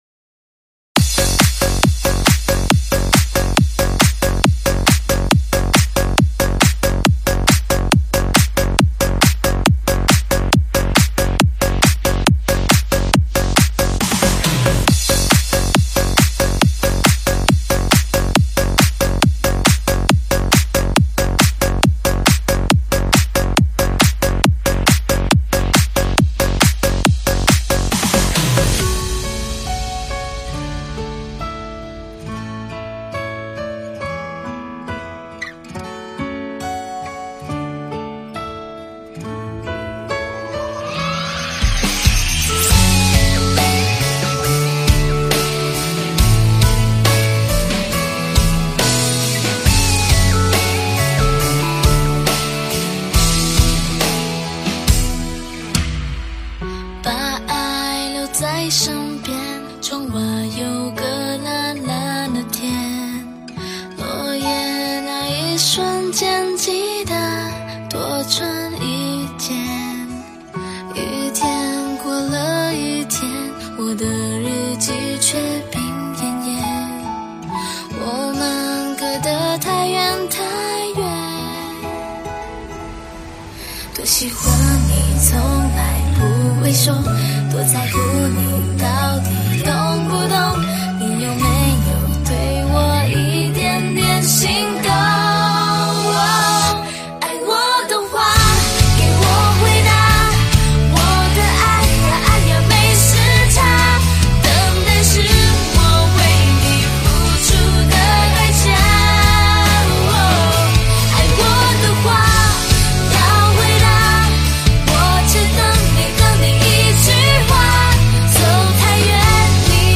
试听文件为低音质，下载后为无水印高音质文件 M币 7 超级会员 M币 4 购买下载 您当前未登录！